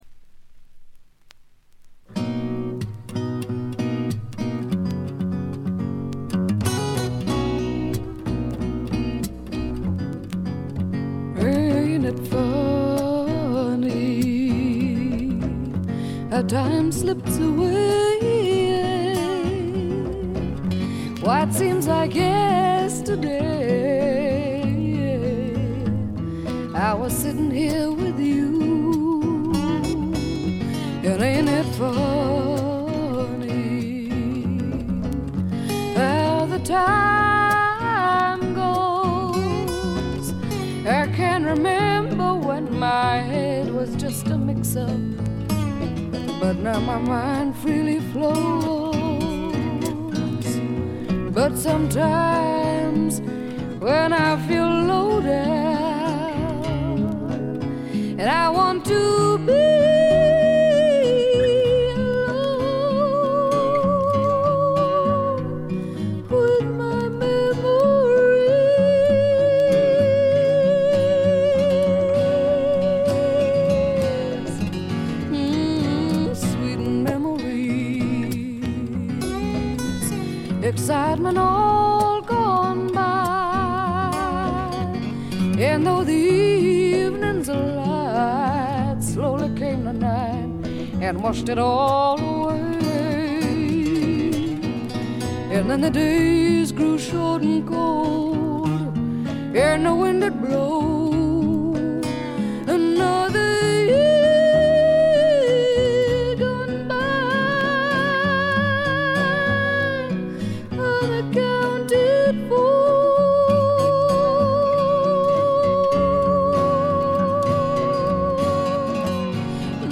試聴曲は現品からの取り込み音源です。
Recorded at Nova Sound Recording Studios, London